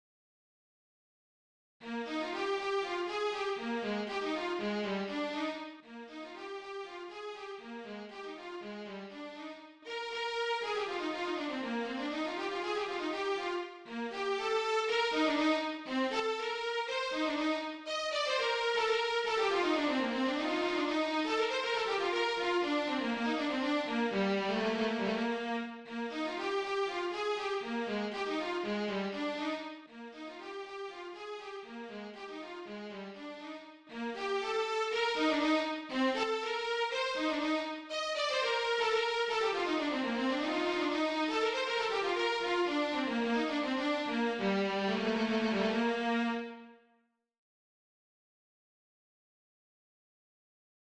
Baroque
DIGITAL SHEET MUSIC - VIOLIN SOLO